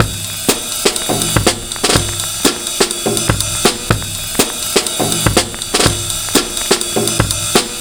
Breakbeat 2
Sizzle 123bpm